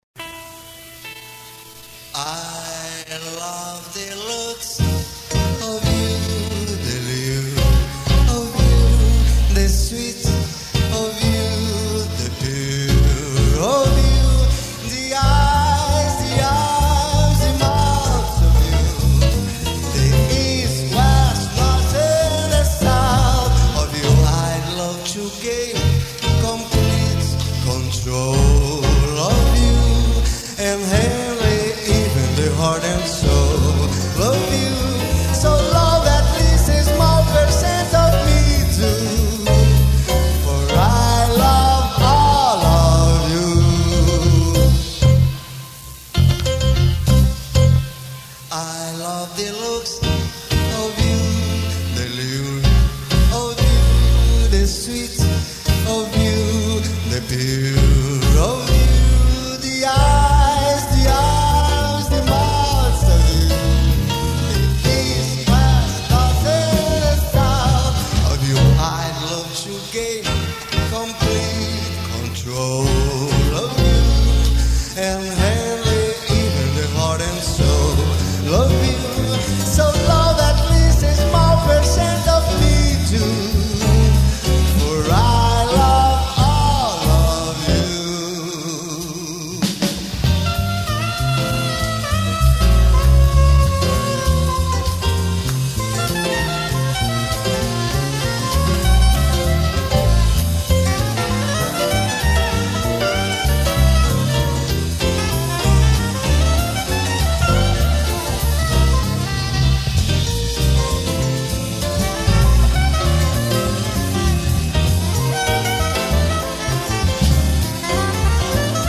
255   05:28:00   Faixa:     Jazz